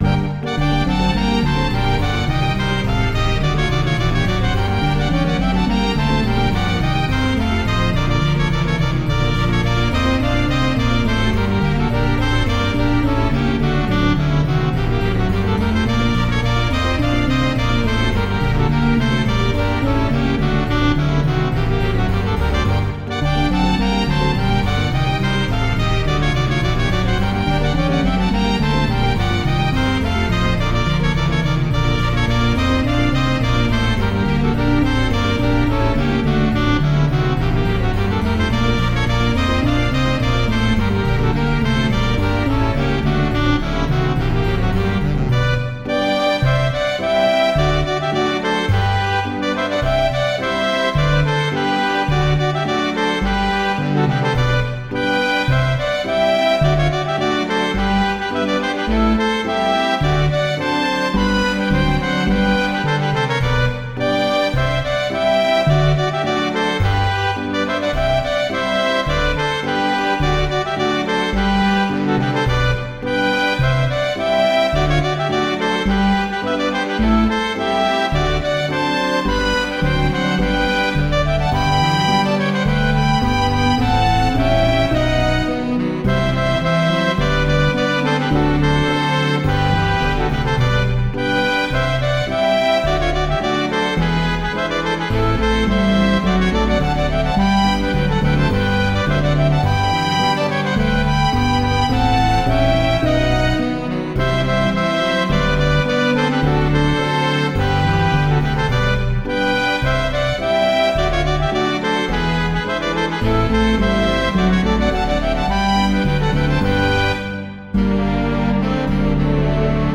Folksong
String quartet
Piano trio
String quintet